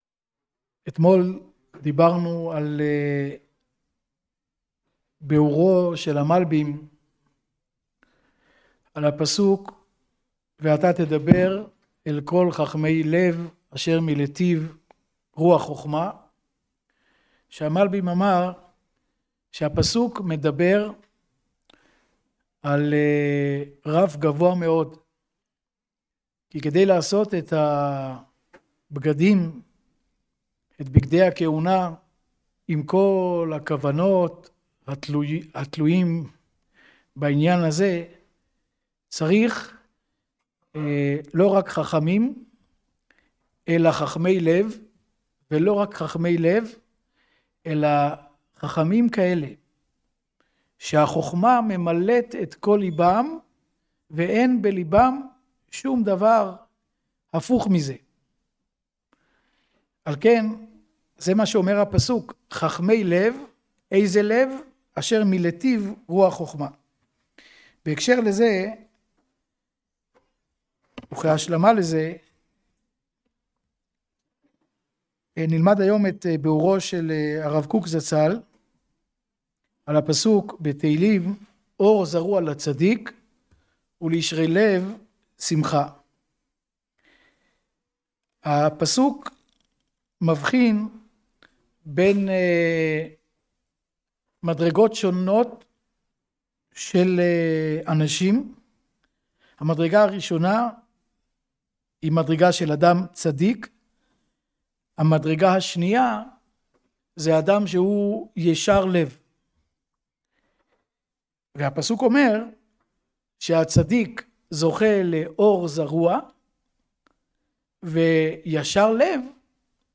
שיעור יומי קצר בפרשת השבוע ובענייני דיומא